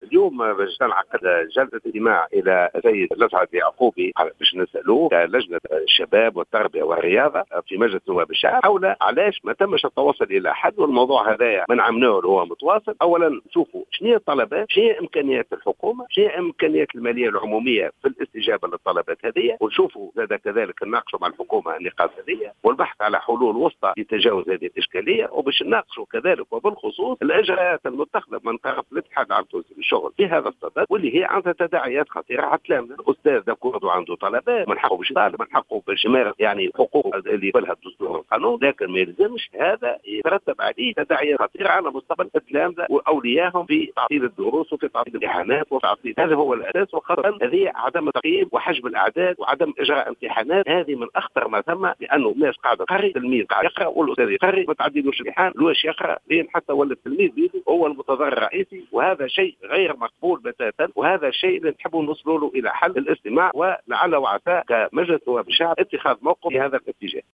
أكد عضو لجنة التربية بالبرلمان جلال غديرة في تصريح للجوهرة اف ام أنه سيتم اليوم...